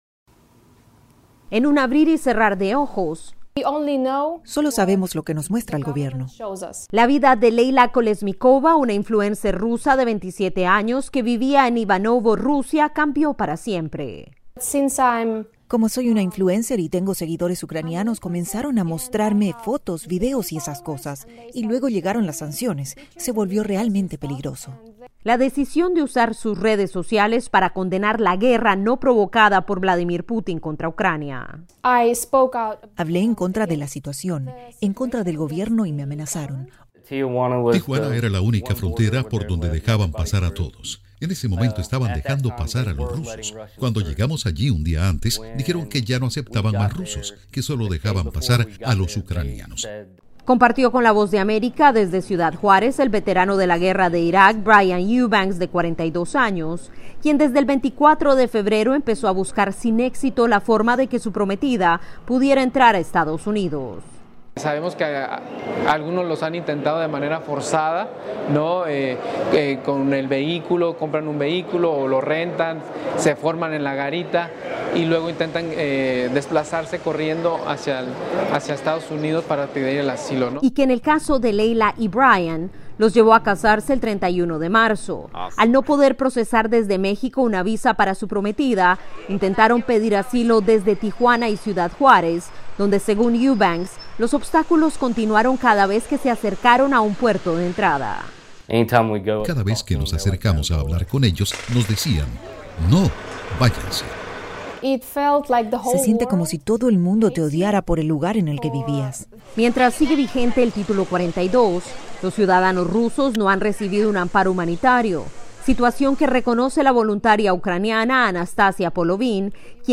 habló con una joven rusa que siguió los pasos de los ucranianos en busca de asilo, pero las puertas para ellos están cerradas.